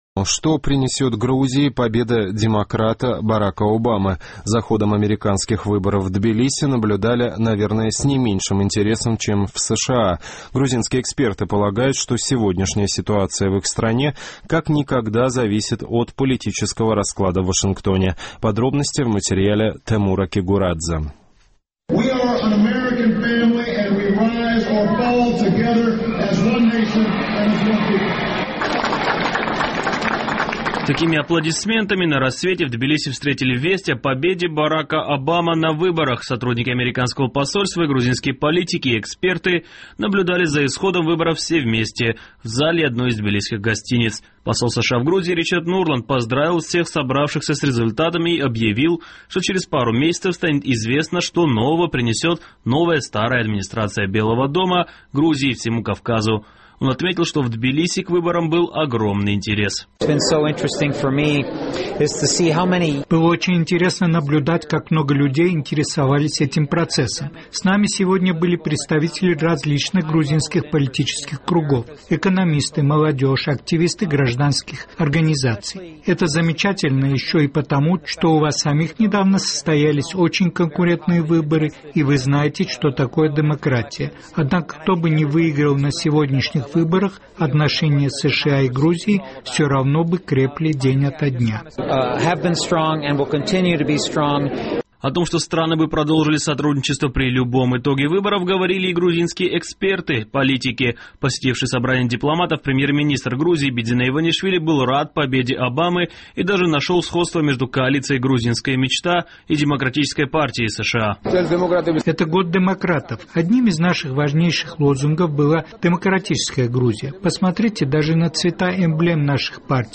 Аплодисментами на рассвете встретили сотрудники американского посольства и грузинские гости весть о победе Барака Обамы на выборах.